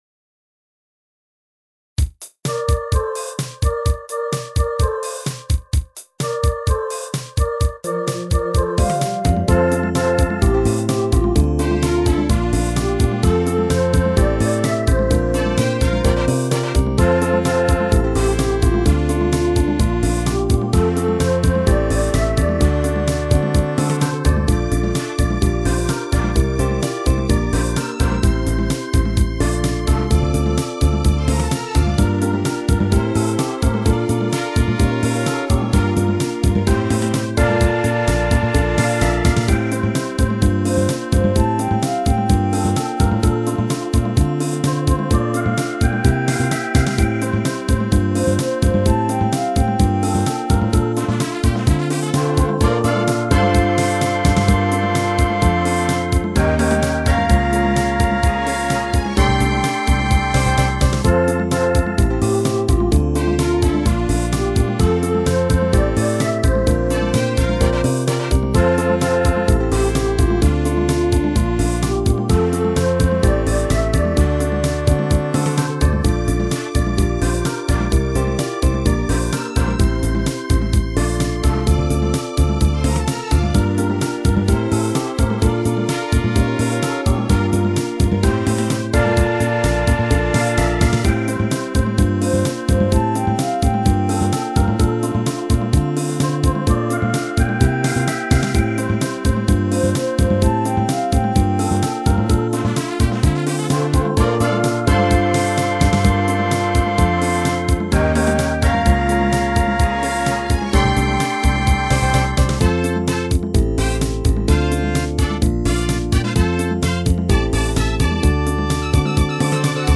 ボーカル4枚は全て左右に振ってみた。ところどころ経過コードを追加したりコードを変えたりもしている。